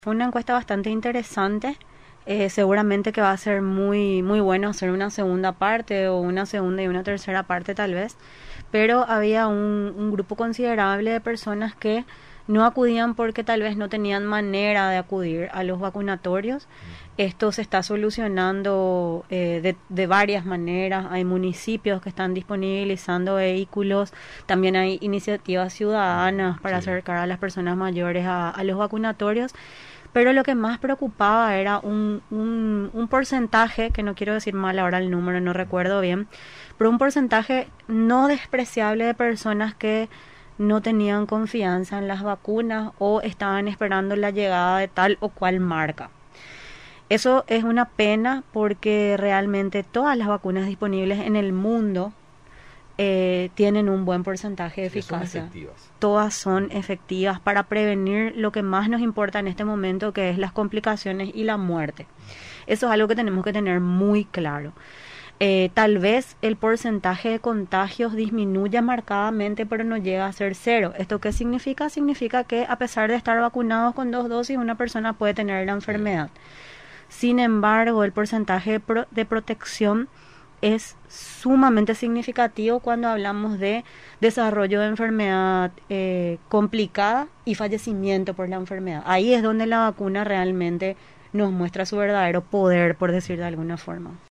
En el bloque «VacunatePY», la Directora de Enfermedades Transmisibles del Ministerio de Salud, Viviana de Egea, visitó los estudios de Radio Nacional del Paraguay, ocasión en la que mencionó que este martes pueden acuden a los vacunatorios todos los adultos mayores de 60 años cumplidos con terminación de cédula 2 y 3.